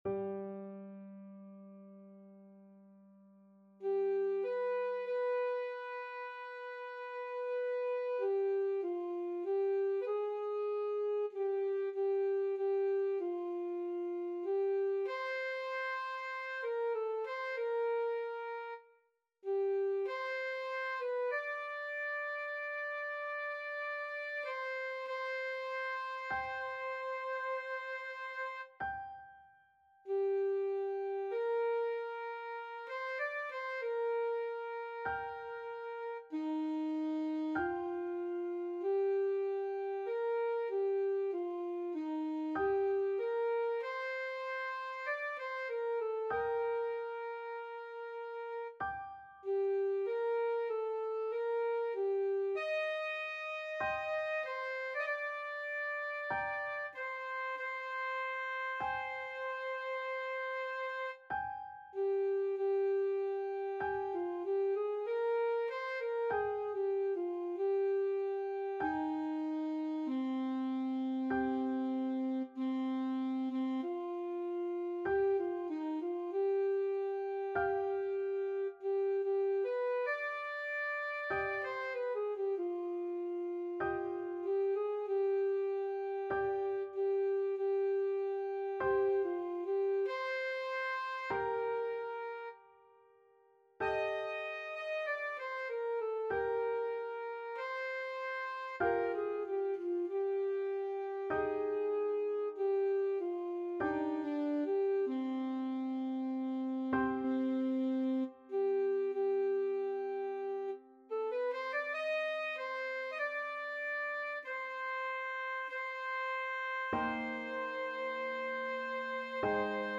Alto Saxophone
(all shown in Concert Pitch)
4/4 (View more 4/4 Music)
Lent =48
Classical (View more Classical Saxophone Music)